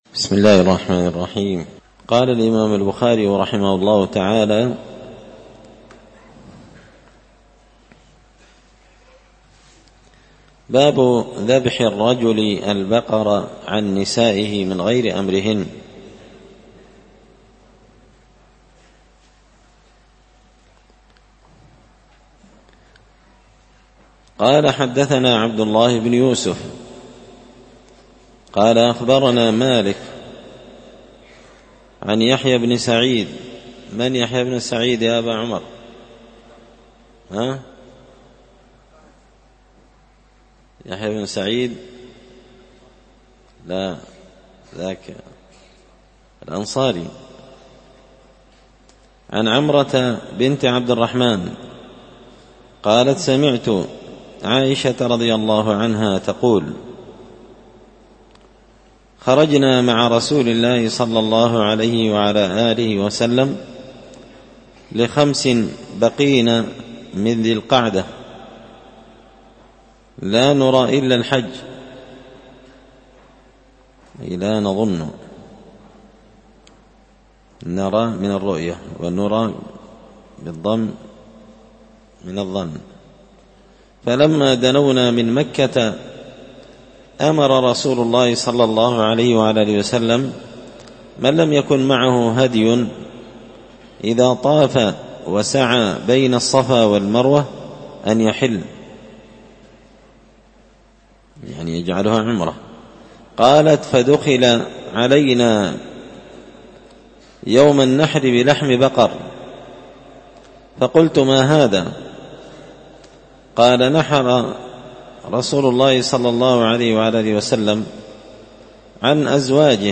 كتاب الحج من شرح صحيح البخاري – الدرس 103